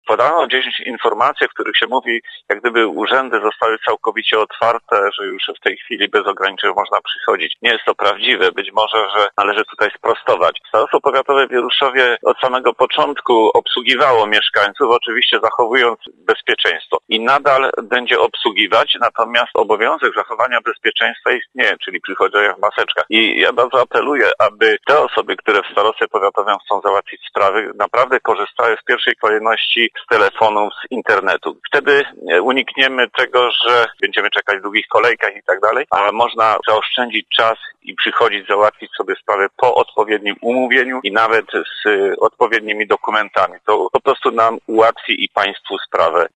Fakt, że cześć z nich otwiera się na petentów, nie znaczy, że przestają obowiązywać środki ostrożności – mówił w rozmowie z nami wicestarosta powiatu wieruszowskiego, Stefan Pietras.